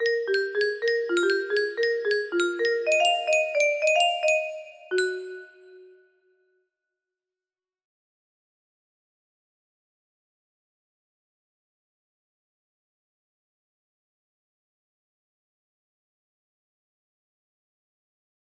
Aoi mix a1 music box melody